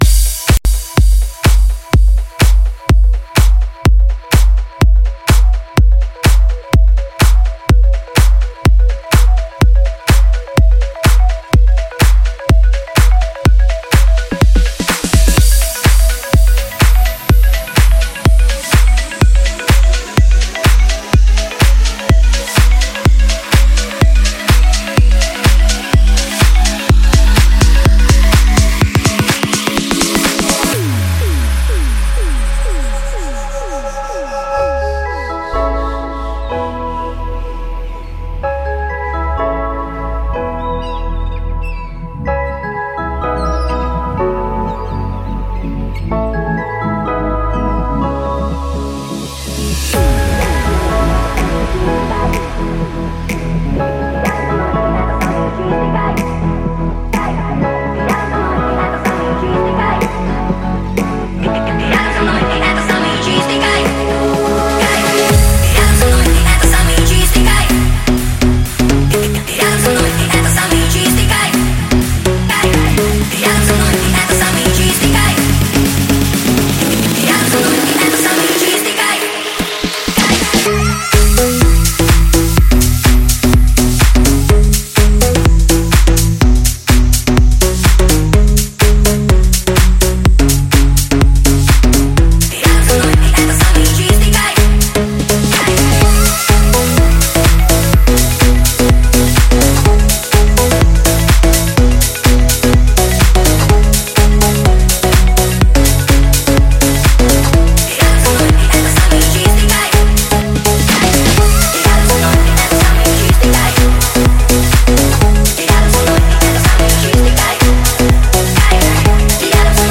Электроника
Жанр: Жанры / Электроника